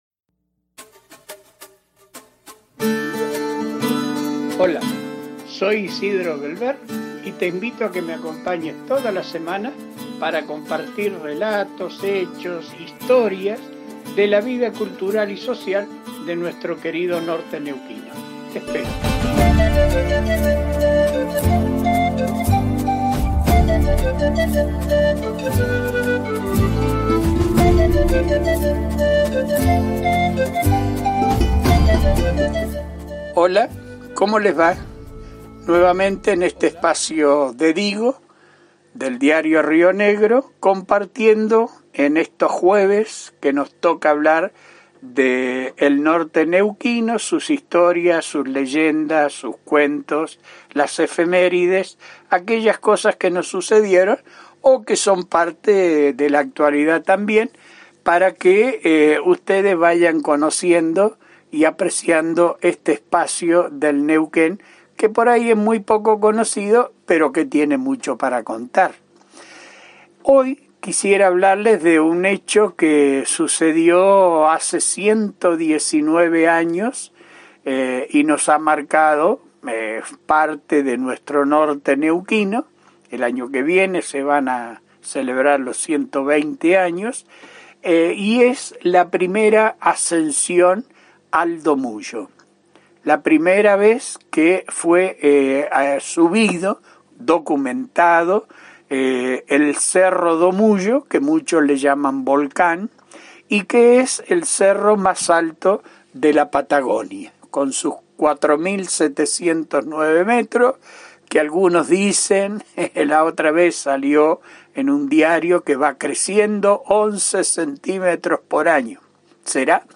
10-primer-ascenso-al-domuyo-historias-del-norte-neuquino.mp3